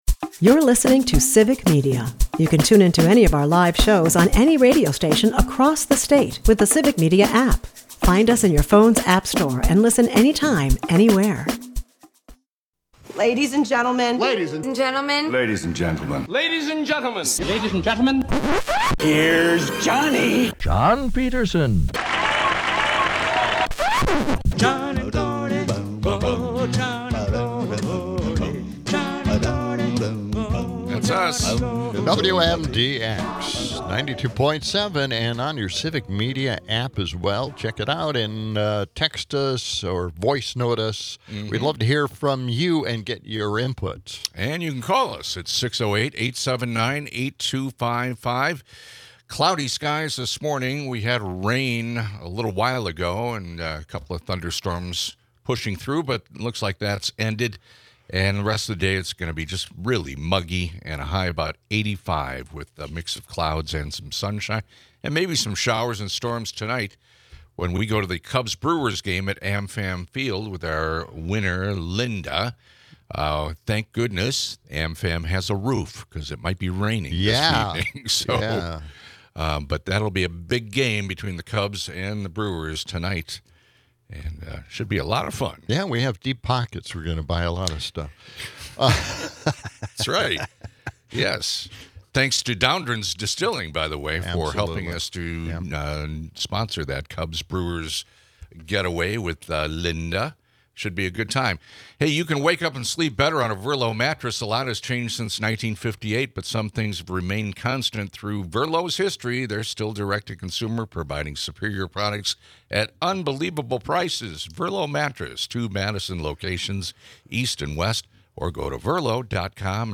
They critique a contentious EU trade deal and revel in Jeff Daniels' iconic speeches on America's state. Weather updates and witty banter abound as they gear up for a night at the ballpark.